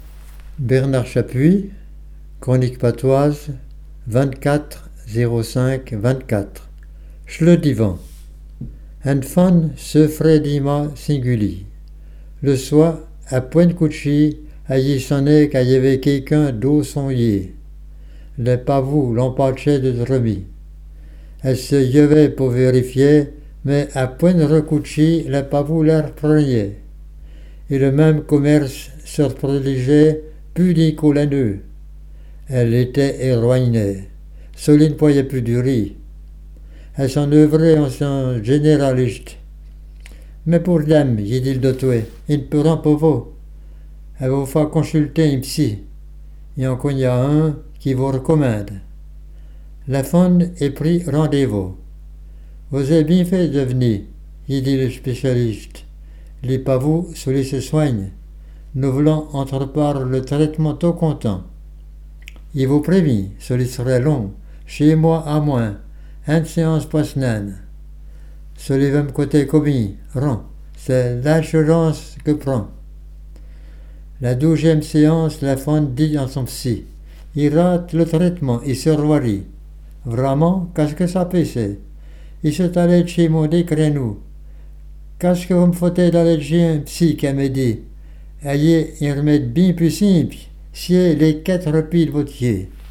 Djasans Patois Jurassien